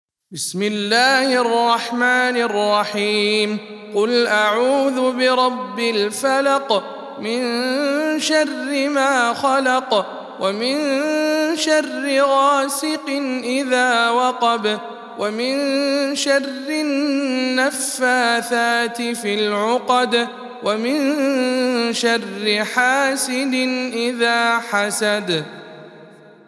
سورة الفلق _ رواية الدوري عن الكسائي